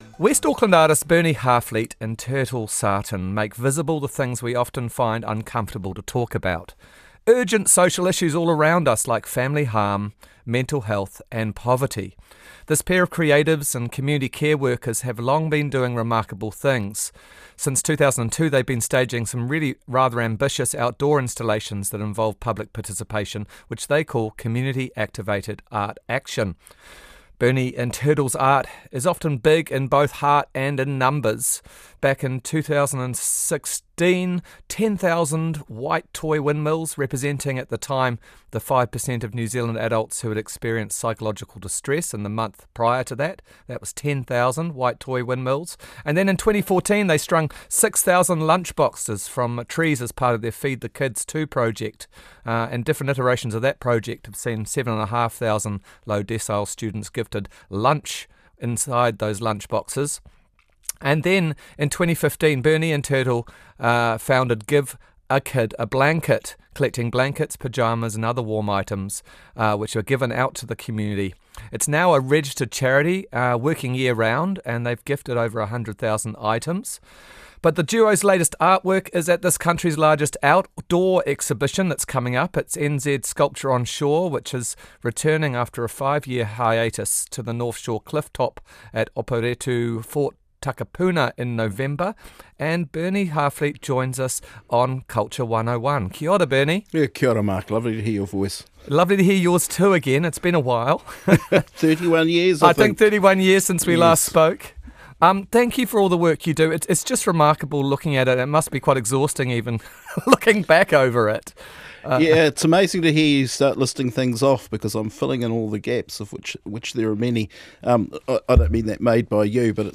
Radio NZ Arts - Programme Interview